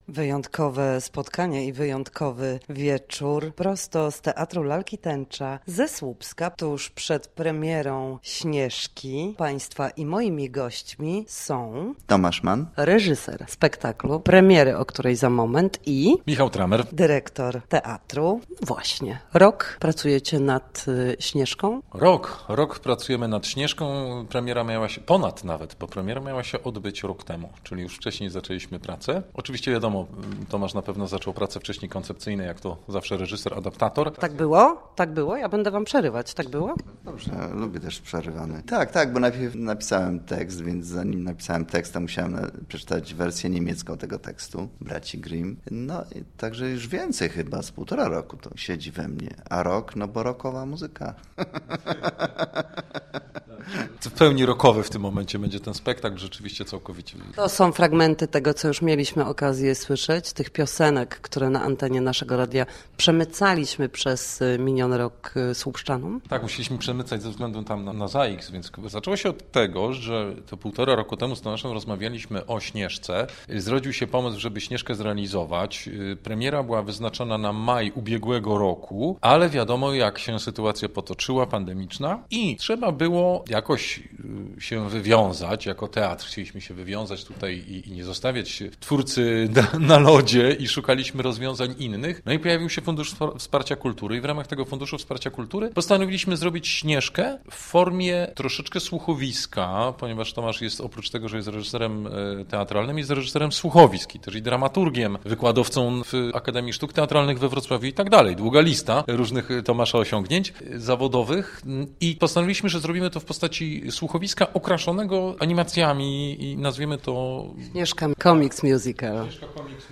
W poniższym materiale także niespodzianka muzyczna.